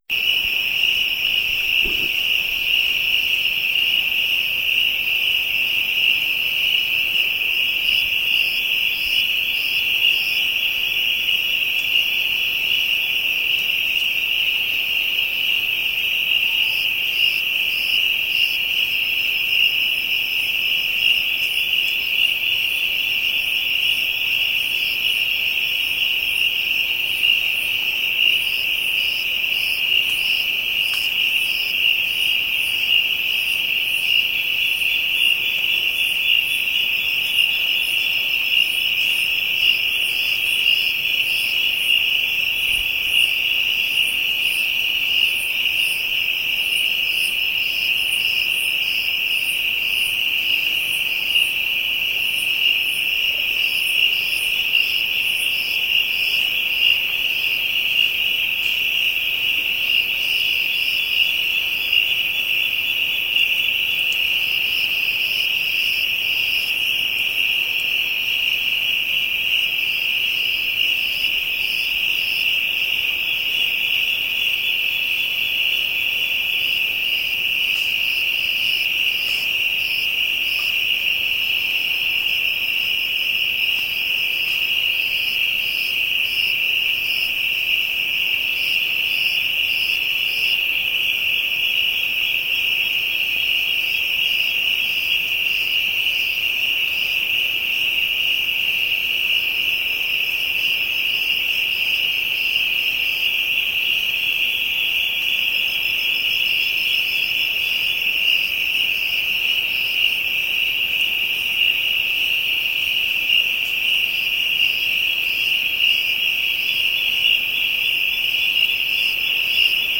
Original creative-commons licensed sounds for DJ's and music producers, recorded with high quality studio microphones.
african insects ambient.wav
african_insects_ambient_FUv.wav